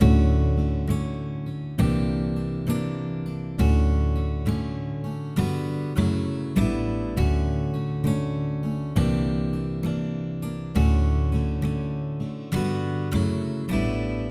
吉他弹奏2
描述：在原声吉他上安静地演奏和弦，标准调音，无变调演奏。
Tag: 声学 弹拨 吉他弦 吉他 弦排列